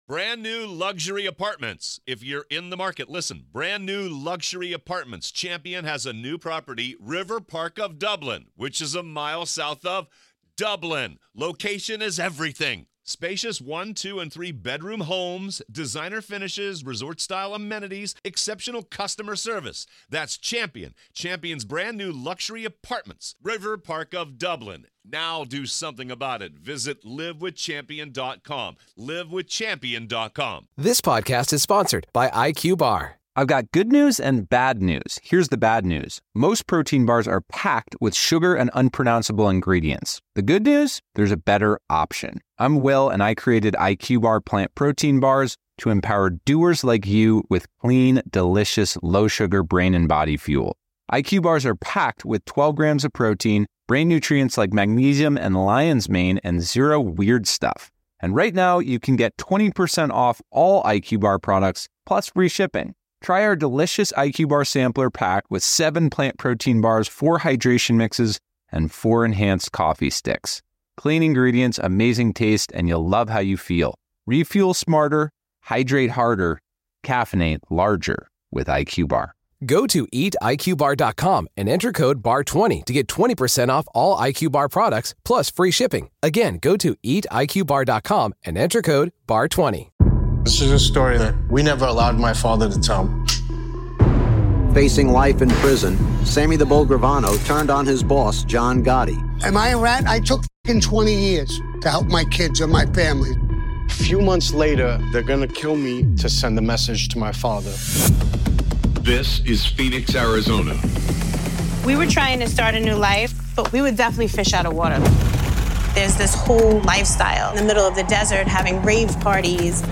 The full interview is split into 2 parts on both of our YouTube channels.